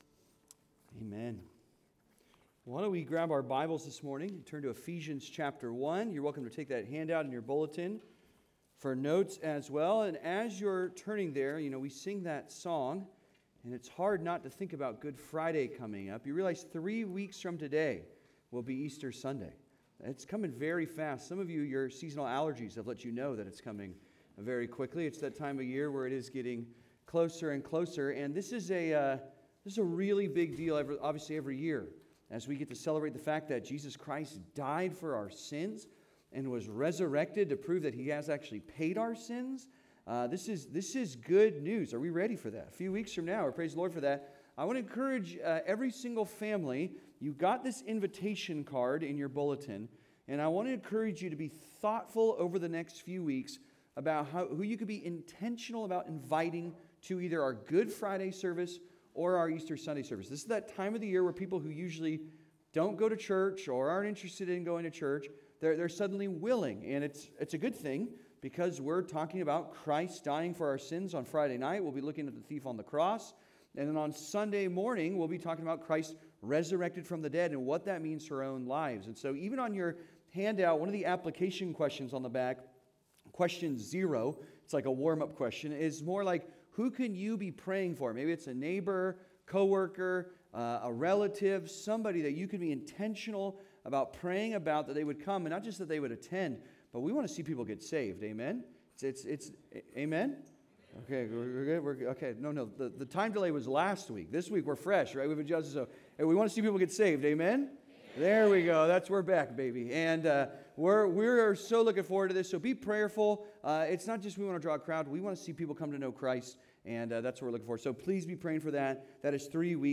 Happy to Pray for You (Sermon) - Compass Bible Church Long Beach